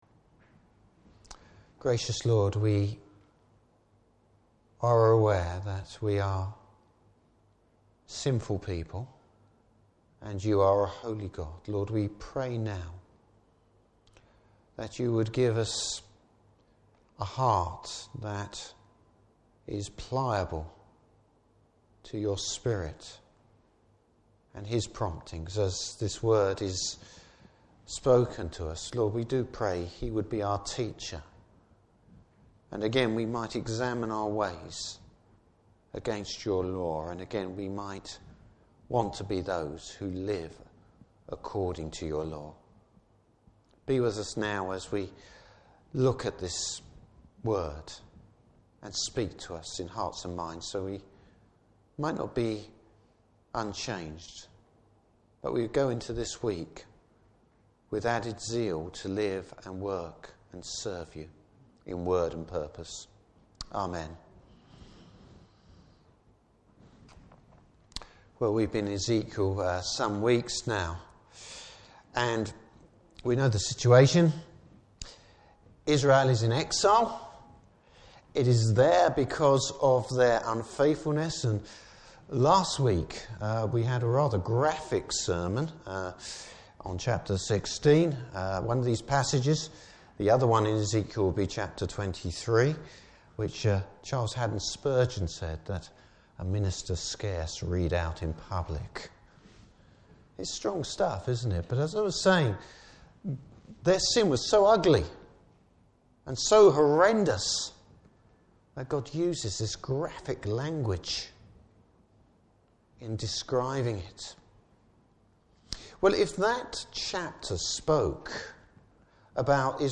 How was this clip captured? Service Type: Evening Service Bible Text: Ezekiel 18.